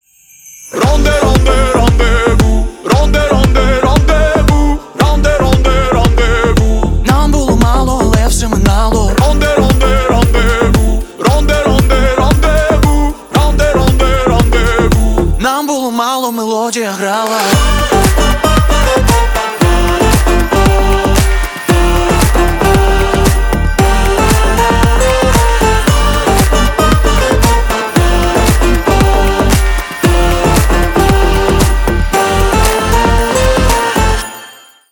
Ремикс
клубные # громкие # новогодние